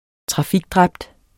Udtale [ -ˌdʁabd ]